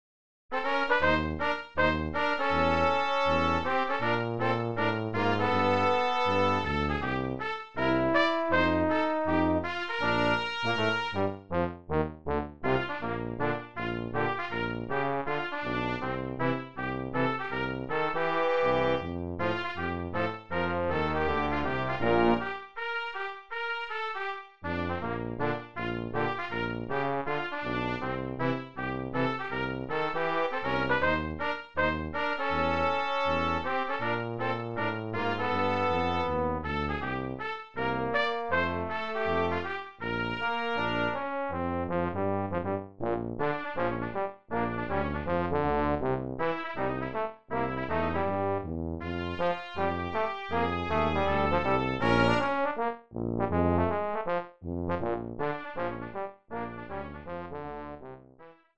Brass Trio TTT